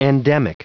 Prononciation du mot endemic en anglais (fichier audio)
Prononciation du mot : endemic